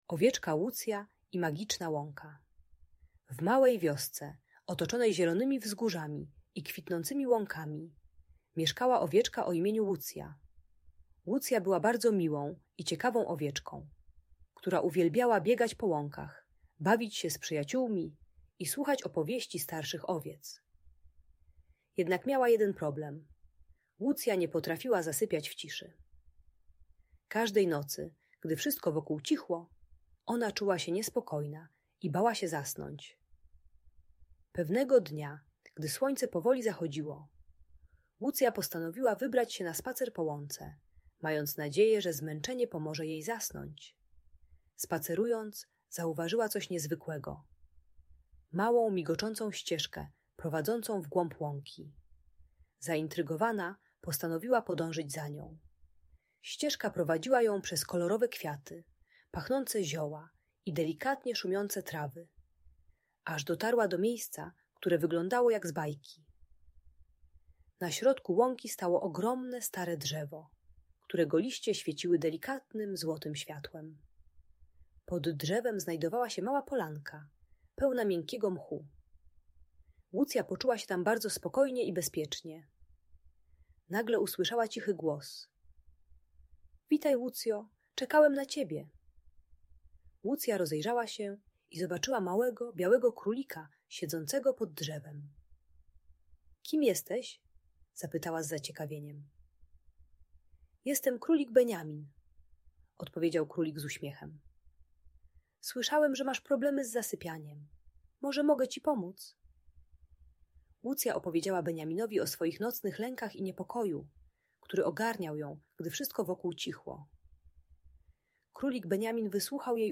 Ta bajka dla dziecka które ma problemy z zasypianiem uczy techniki wizualizacji - wyobrażania sobie spokojnego miejsca przed snem. Darmowa audiobajka usypiająca dla przedszkolaków z lękiem przed zasypianiem.